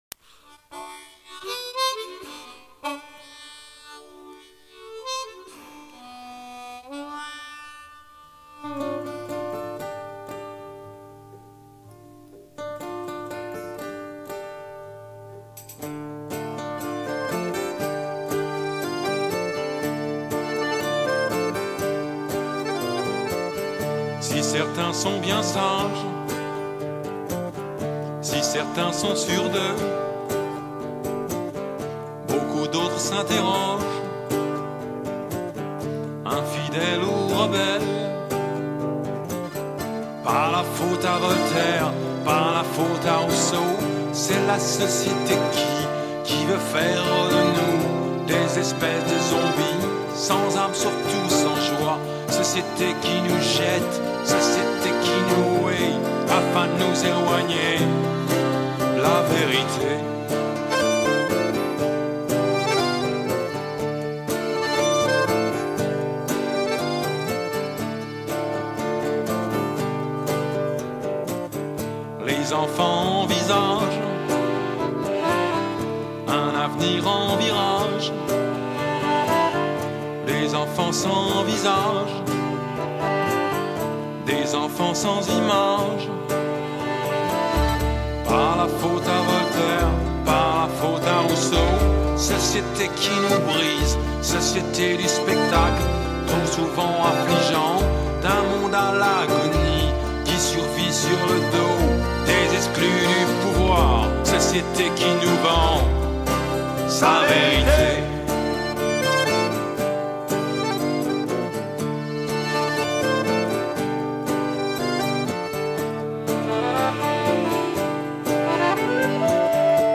rythm guitar + acoustic guitars
lead guitar & slide
bass & choirs
Keyboards & programs
Saxophones